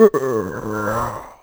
c_zombim3_hit2.wav